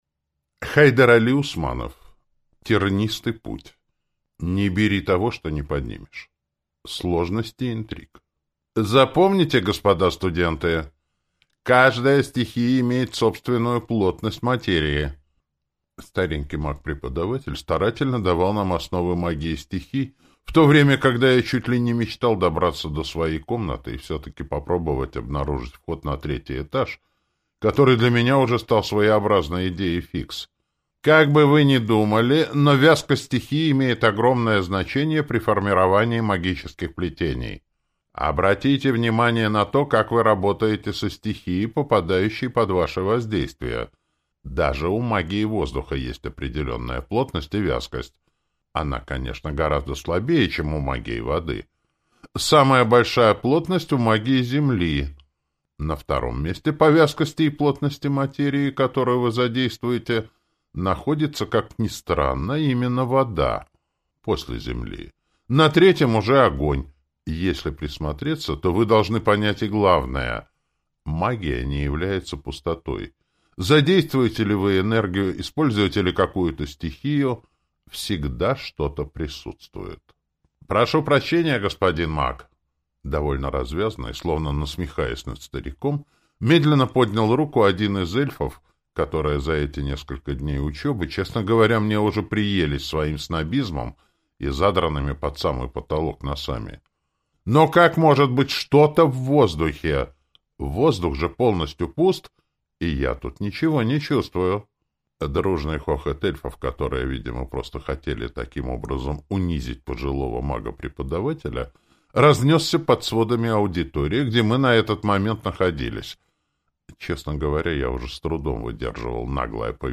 Аудиокнига Тернистый путь. Не бери того, что не поднимешь | Библиотека аудиокниг